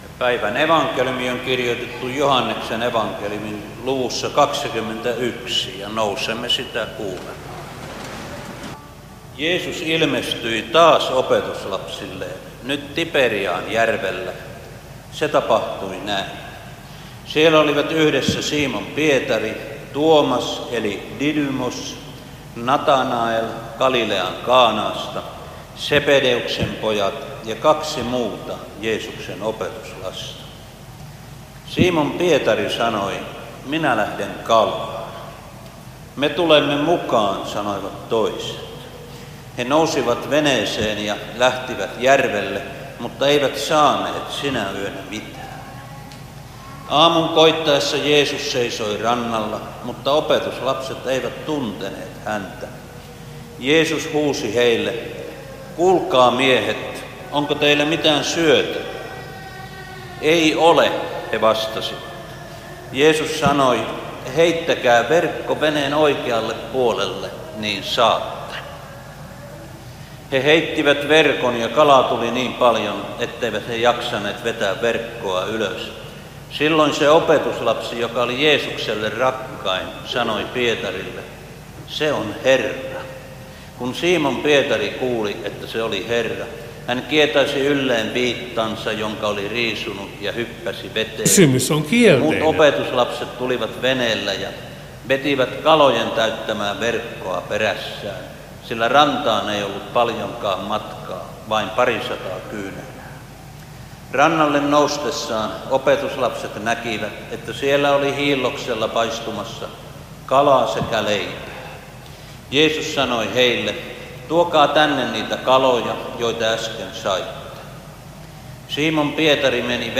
Veteli